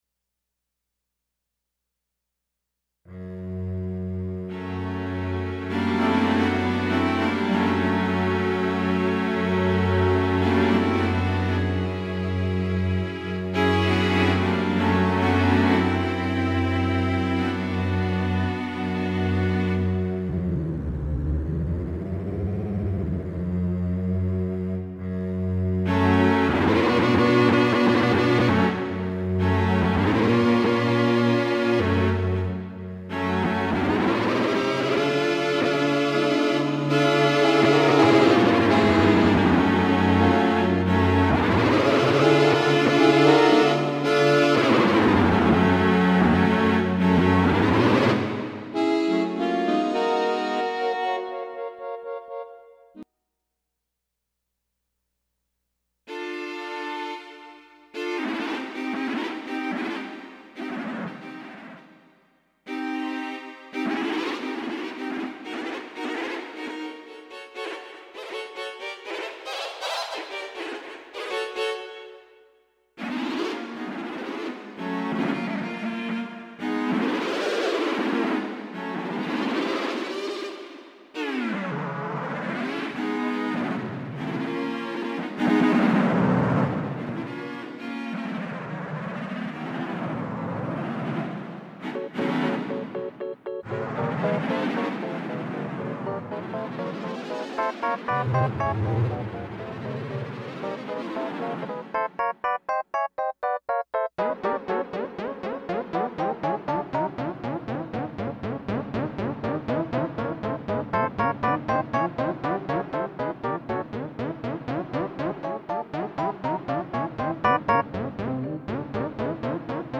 PURE KURZWEIL 2661 ~ A fine Poly voice track